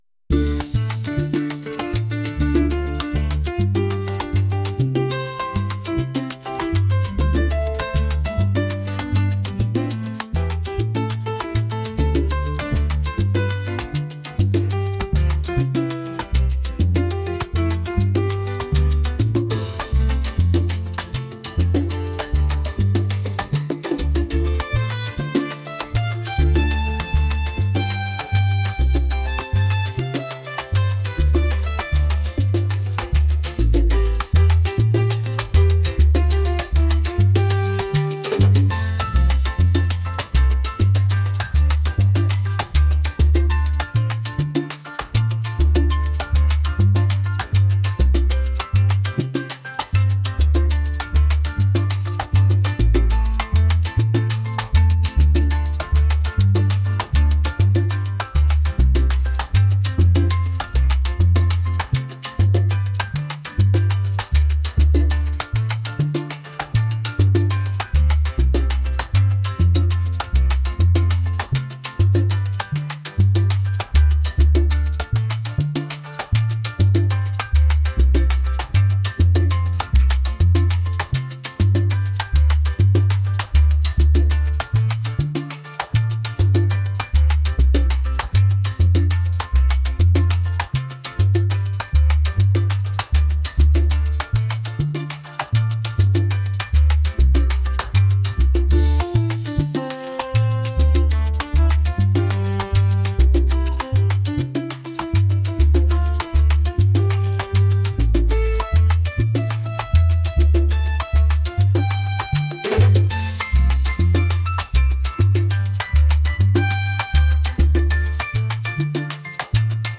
energetic | latin